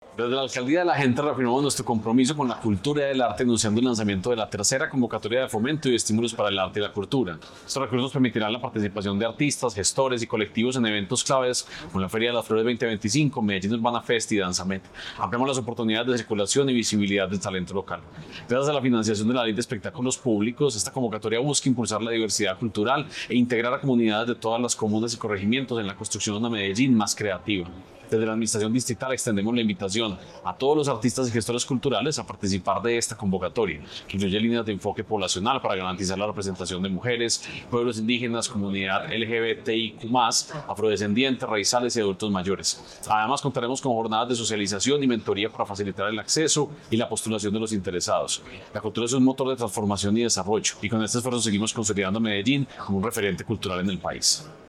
Palabras de Santiago Silva Jaramillo, secretario de Cultura Ciudadana